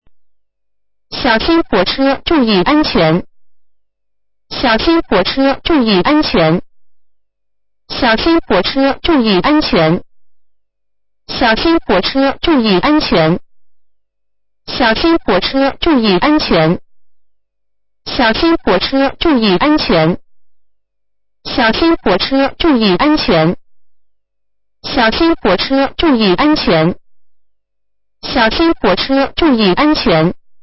音响发铁路道口 常用警钟声，或警钟声加“小心火车，注意安全”的语音提示声。
语言